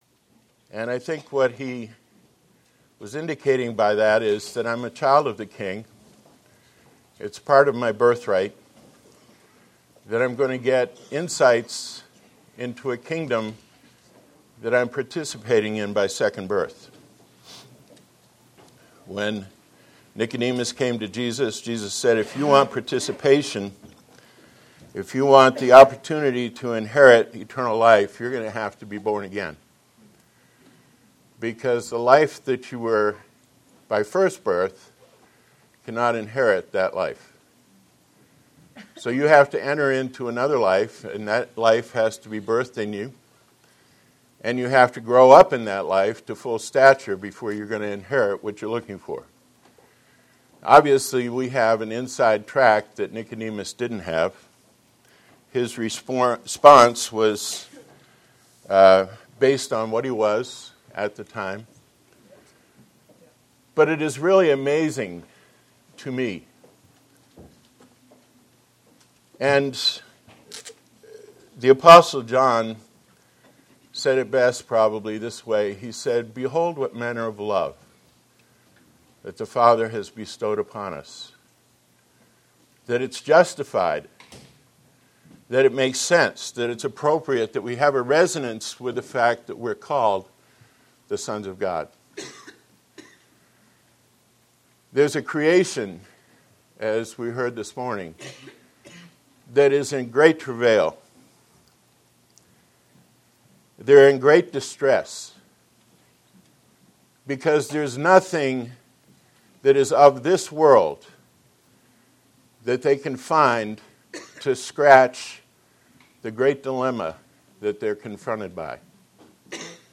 Posted in 2015 Shepherds Christian Centre Convention